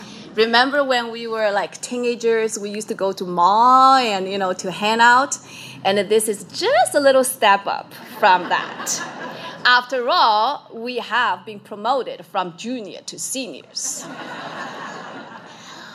Portage’s brand new Senior Center is now open after ribbon-cutting ceremonies on Friday, May 20.